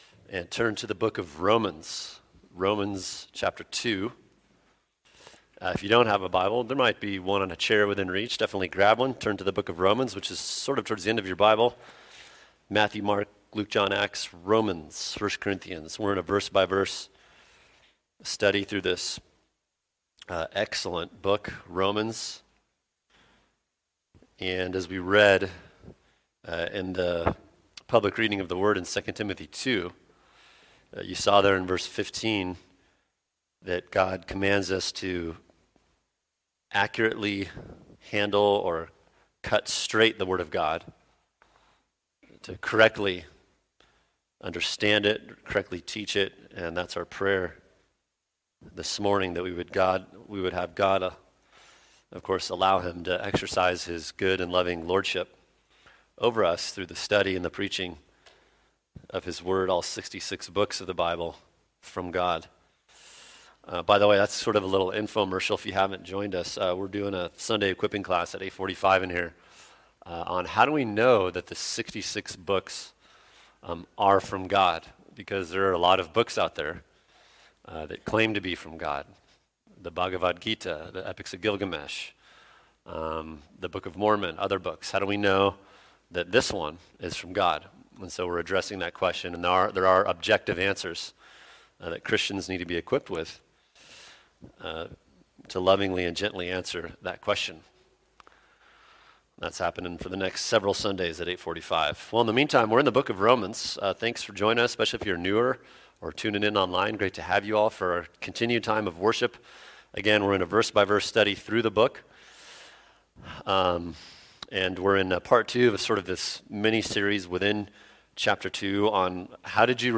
[sermon] Romans 2:14-16 How Did You Respond To Your Knowledge? Part 2 | Cornerstone Church - Jackson Hole